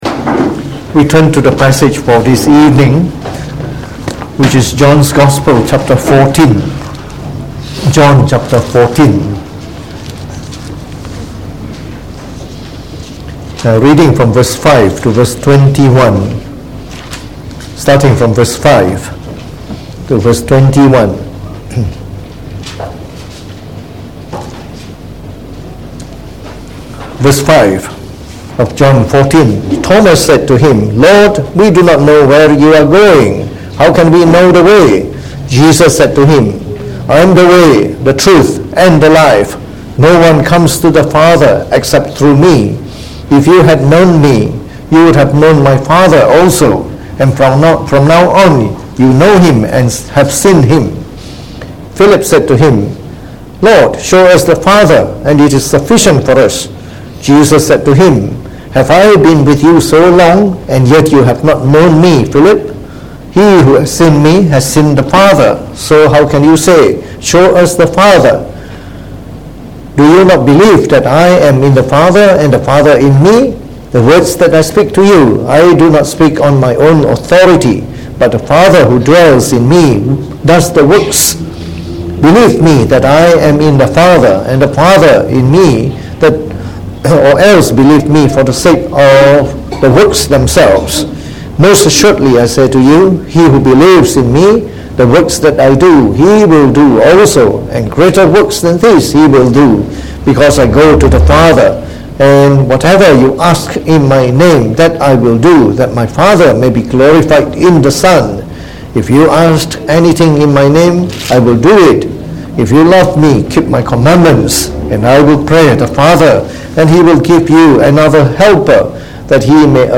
From our series on the Gospel of John delivered in the Evening Service